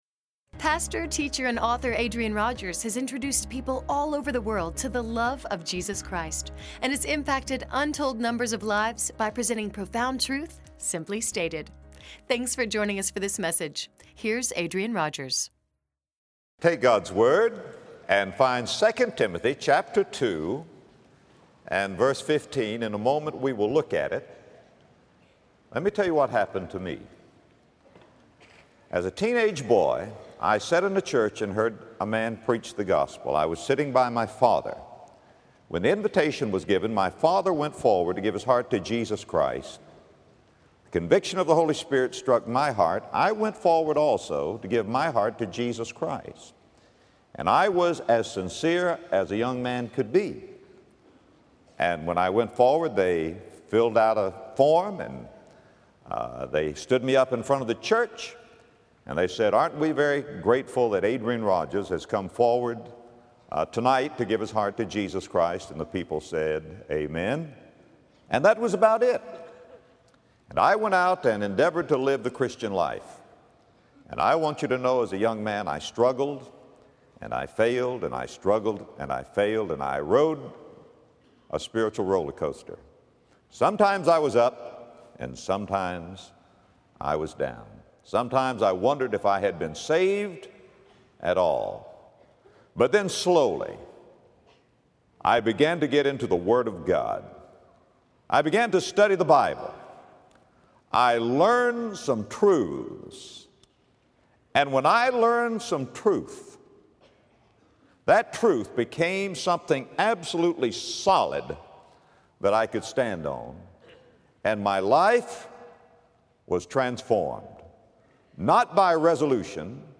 Faithful in Bible Study Podcast